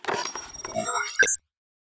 calibrate neutral.ogg